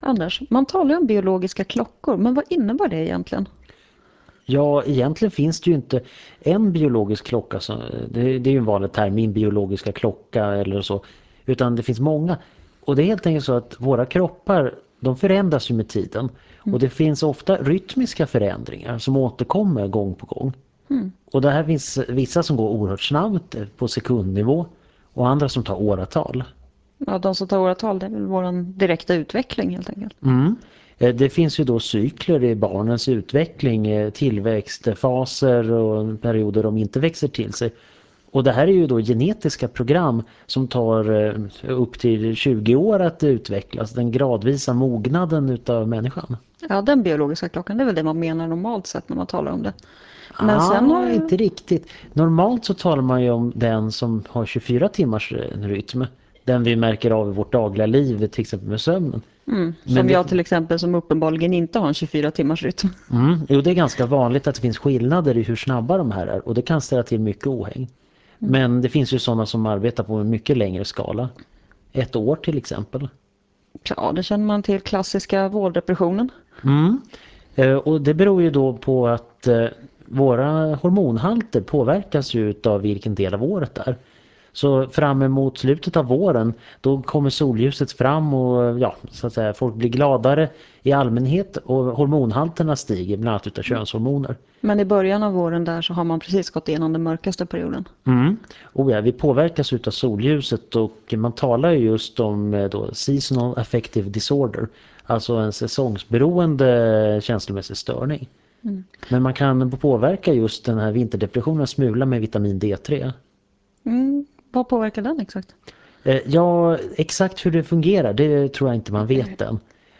Föredraget handlar om biologi och sänds februari 2000 i Etervåg.